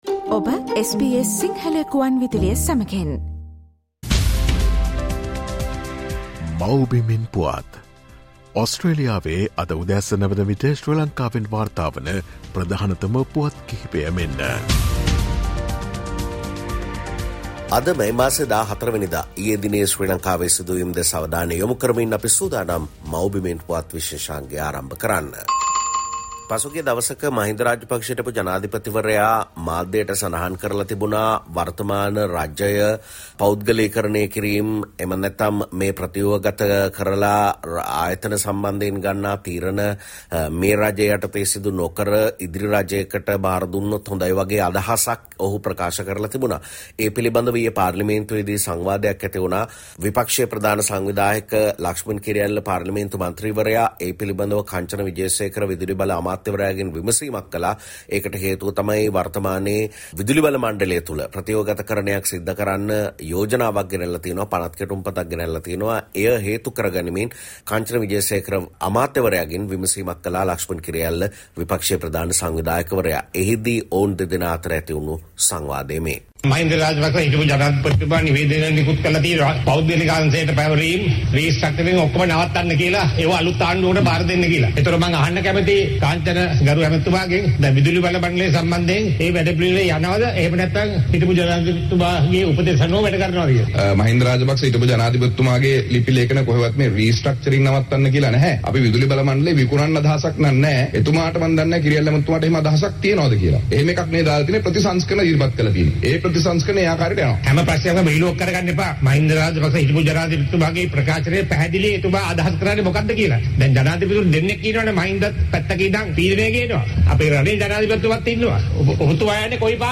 The latest news reported from Sri Lanka as of this morning in Australia time from the “Homeland News” feature